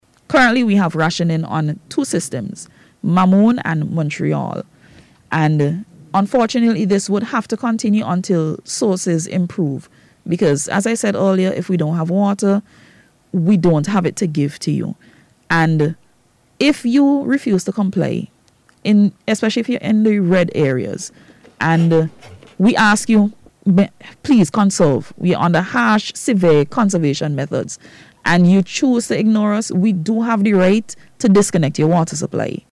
WATER-RATIONING.mp3